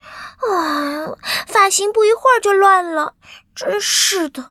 文件 文件历史 文件用途 全域文件用途 Fifi_amb_02.ogg （Ogg Vorbis声音文件，长度4.5秒，99 kbps，文件大小：55 KB） 源地址:游戏语音 文件历史 点击某个日期/时间查看对应时刻的文件。